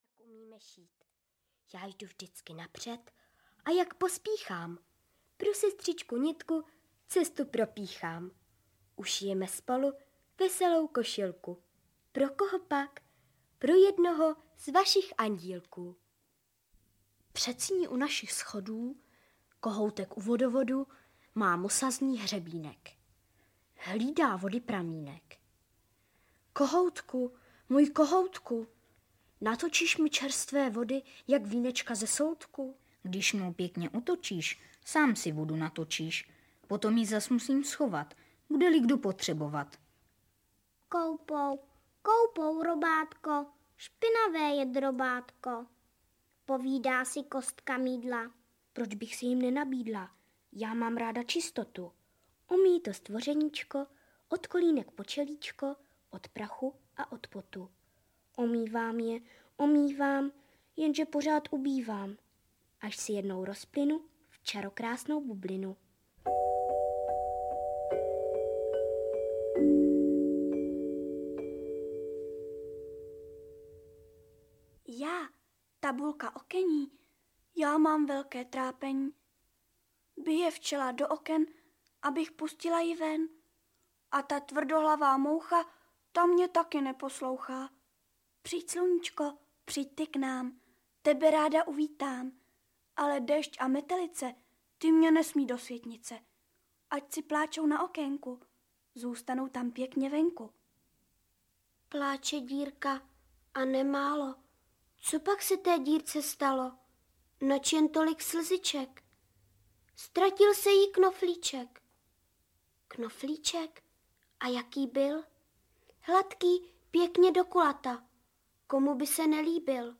Od jehly k mašinkám audiokniha